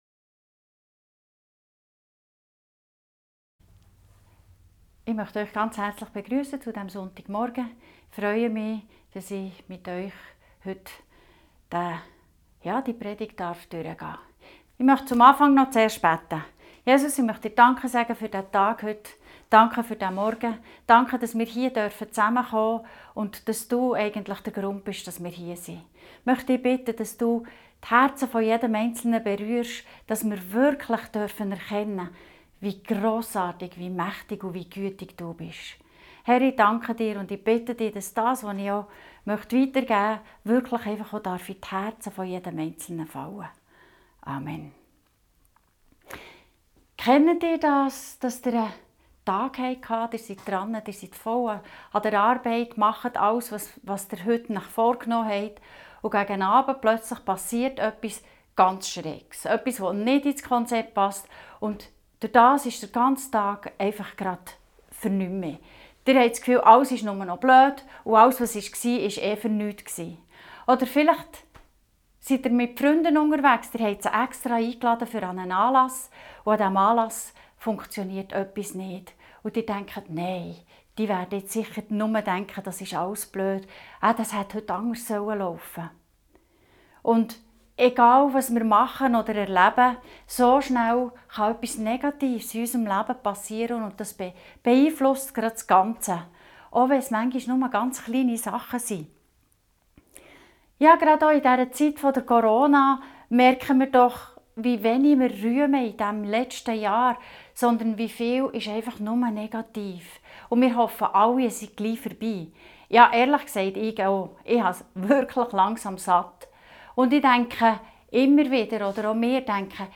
Passage: 1 Thess 5,16-18 Dienstart: Gottesdienst Themen